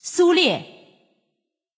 王者荣耀_人物播报_苏烈.mp3